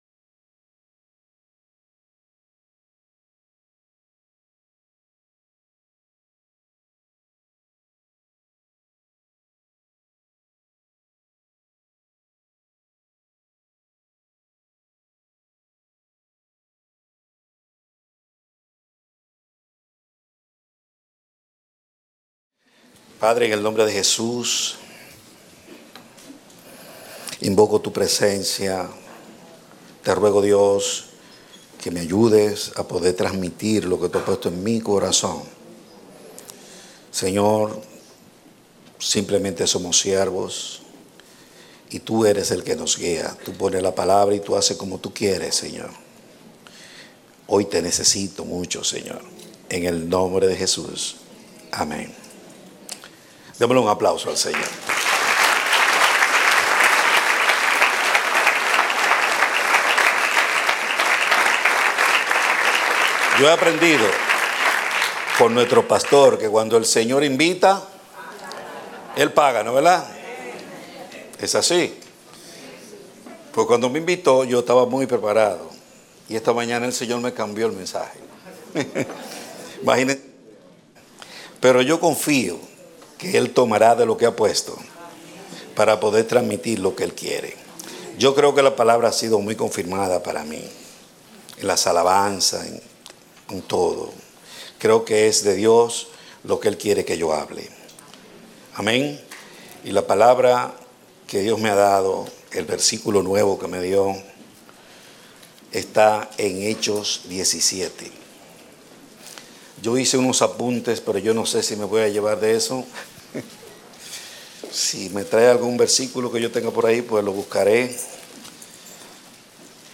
Predicado Domingo 19 de Junio, 2016